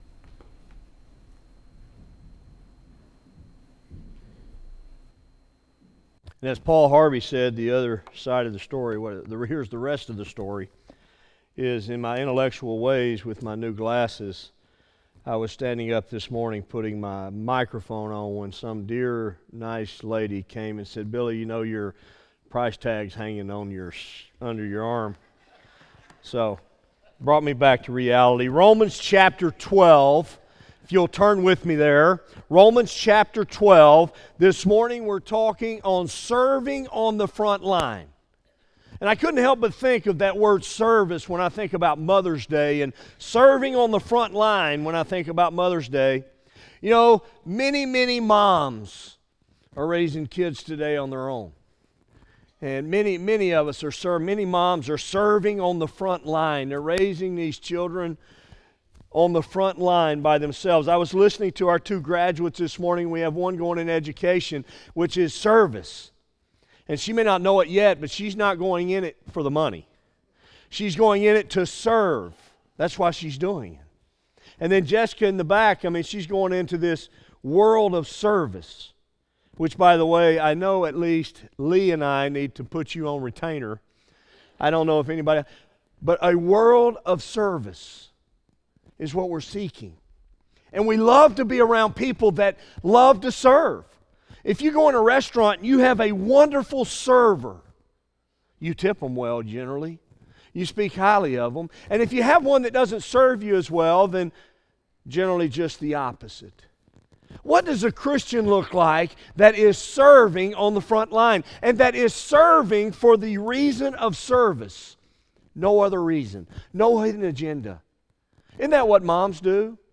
by Office Manager | May 15, 2017 | Bulletin, Sermons | 0 comments